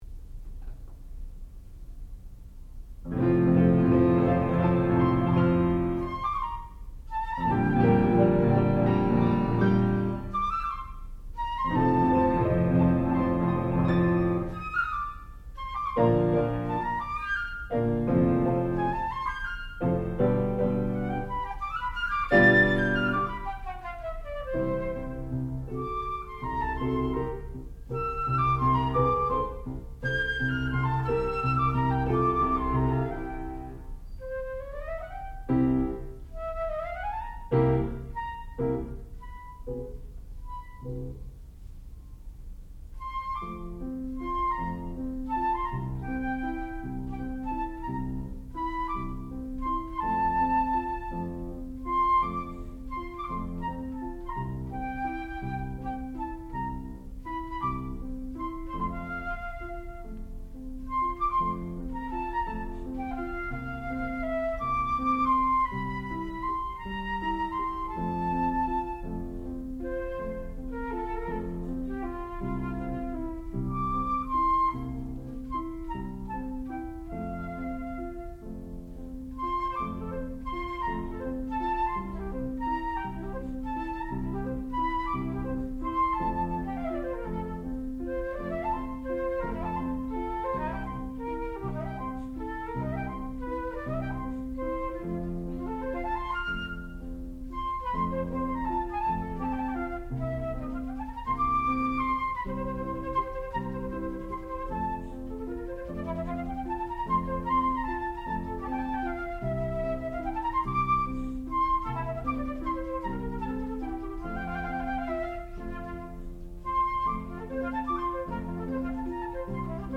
sound recording-musical
classical music
Advanced Recital
flute